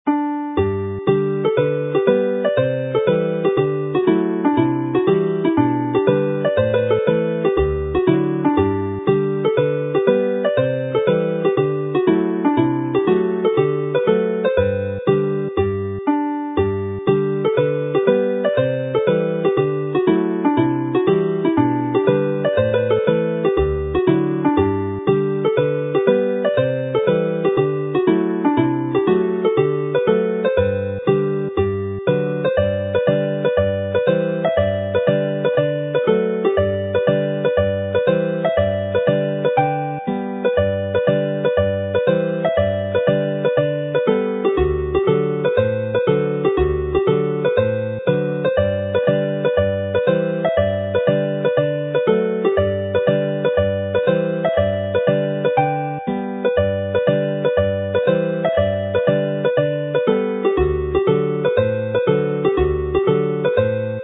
Hornpipes are played with a skip - dotted - but written in plain timing, like a reel, with the understanding that they can be interpreted either as a reel or a hornpipe.
Slow sound versions of the files are also included to make the tunes easier to learn by ear.